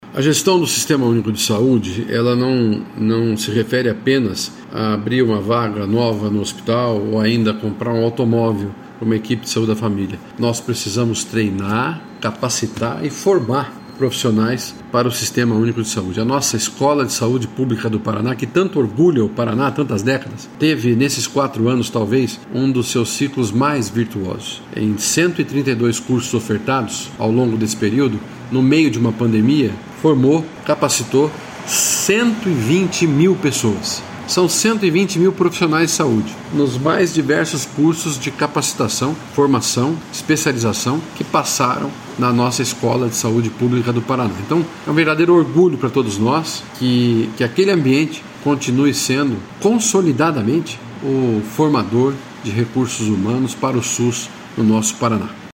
Sonora do secretário da Saúde, Beto Preto, sobre a formação de mais de 123 mil profissionais em quatro anos